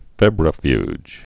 (fĕbrə-fyj)